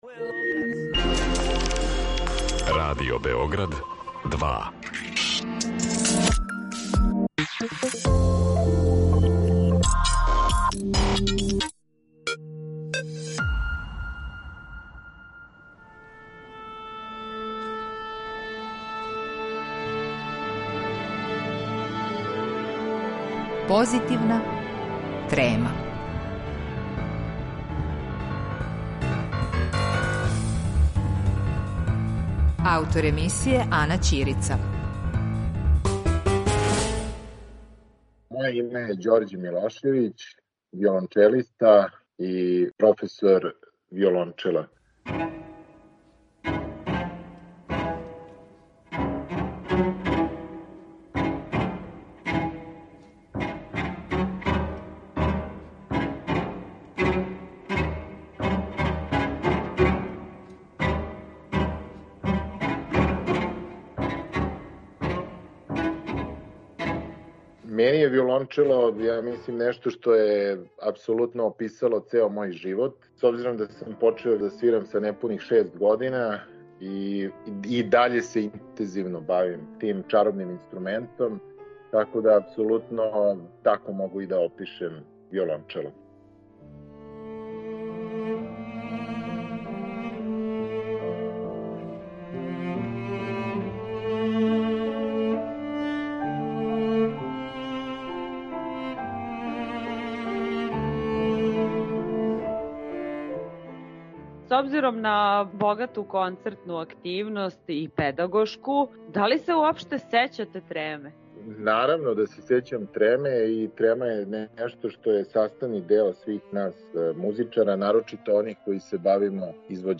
Виолончелиста